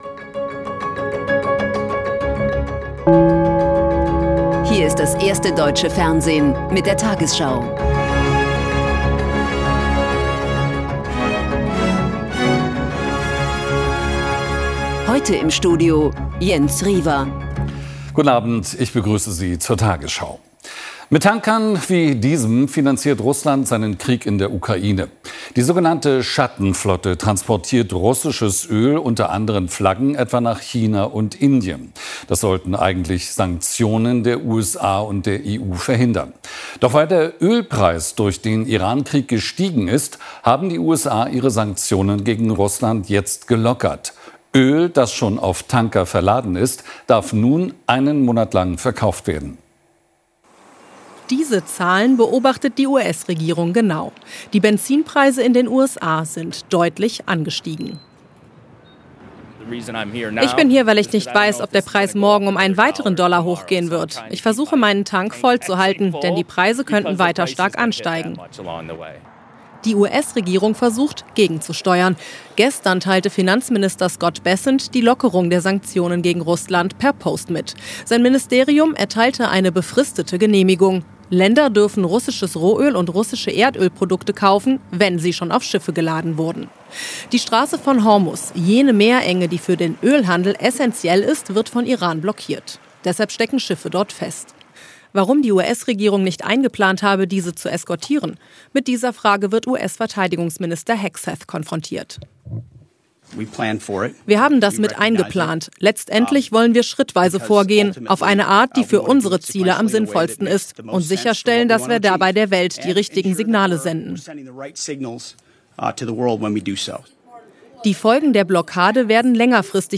Wegen technischer Probleme funktionierte der Übergang zu unserer Schalte nach Moskau nicht. Wir haben die Sendung nachträglich bearbeitet.